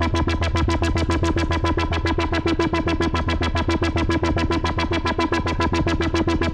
Index of /musicradar/dystopian-drone-samples/Tempo Loops/110bpm
DD_TempoDroneA_110-E.wav